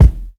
INSKICK04 -R.wav